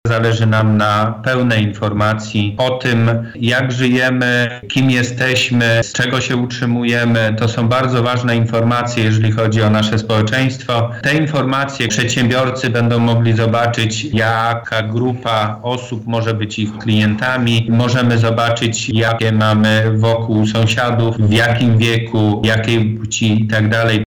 – mówi dyrektor Głównego Urzędu Statystycznego w Lublinie Krzysztof Markowski.